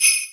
soft-hitnormal.wav